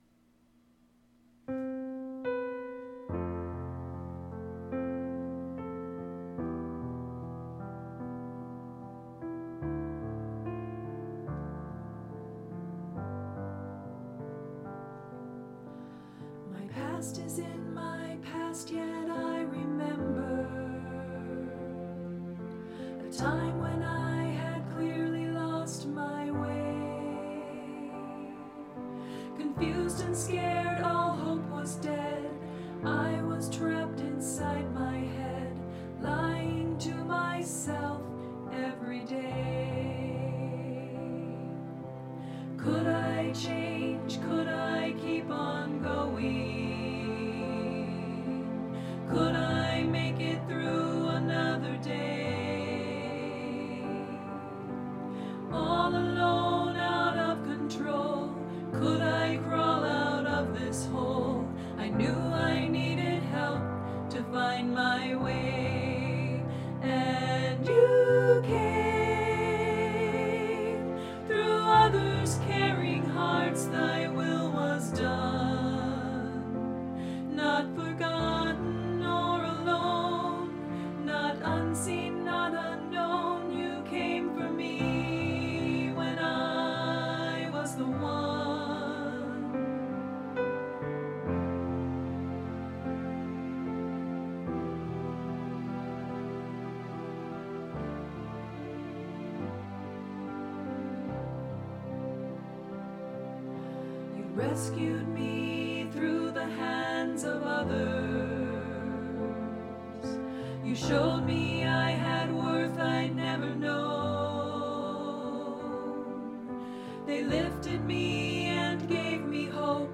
Voicing/Instrumentation: SA , Duet
Vocal Solo Medium Voice/Low Voice